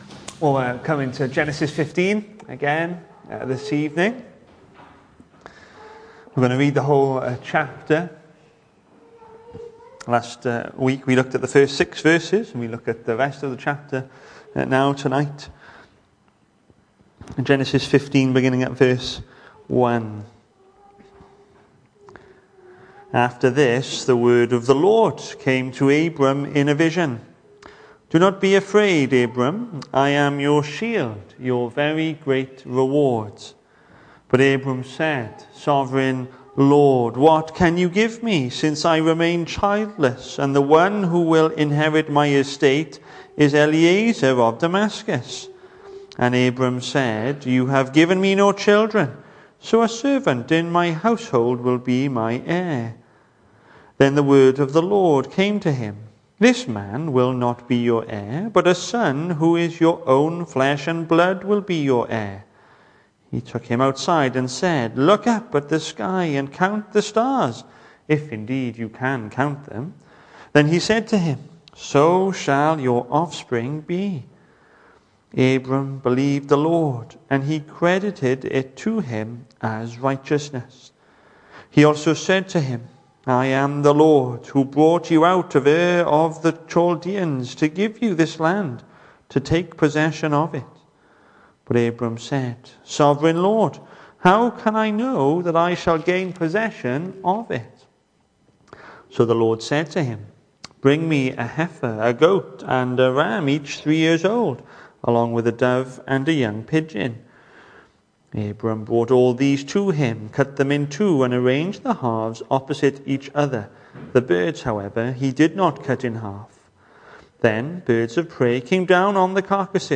Hello and welcome to Bethel Evangelical Church in Gorseinon and thank you for checking out this weeks sermon recordings.
The 12th of October saw us hold our evening service from the building, with a livestream available via Facebook.